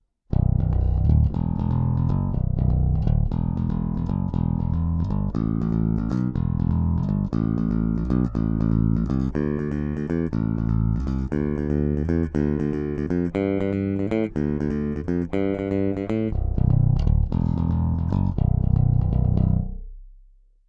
Oba snímače, oba dvojcívka, oba paralelně (trsátko)